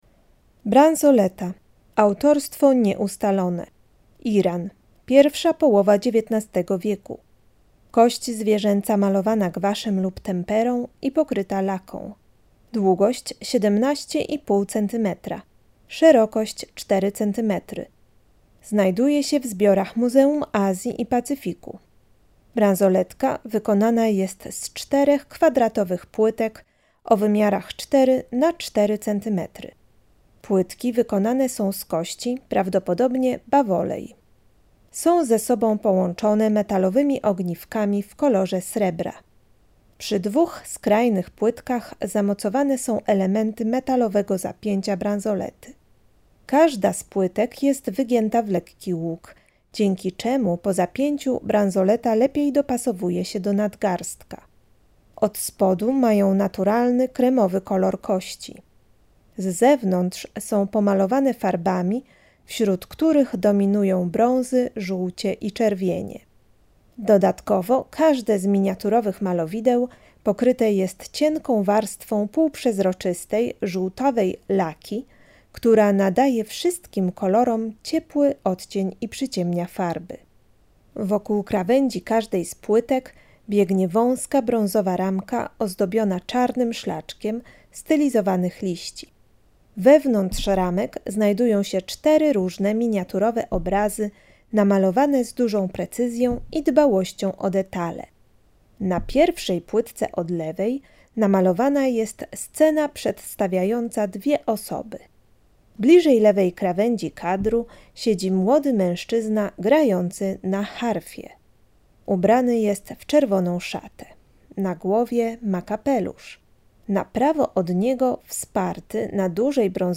Audiodeksrypcja: